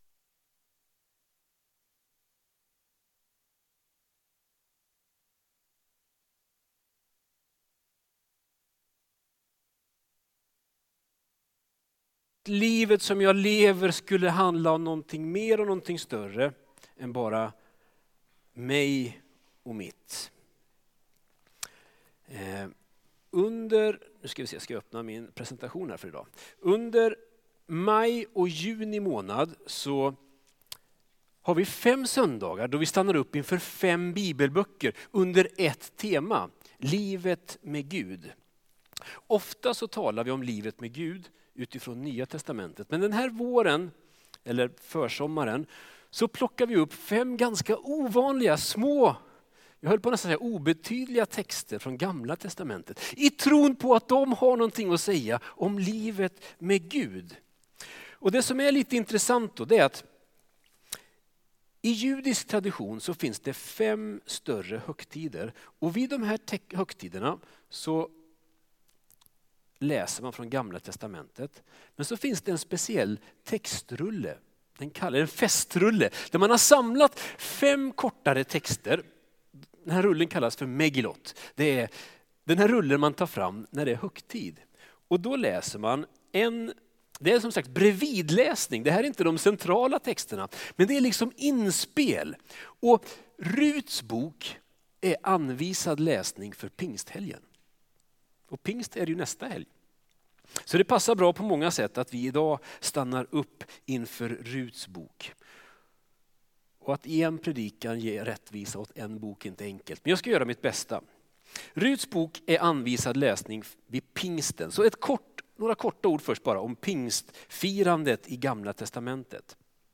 Gudstjänst – Korskyrkan Norrköping